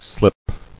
[slip]